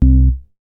MoogShotLivd 006.WAV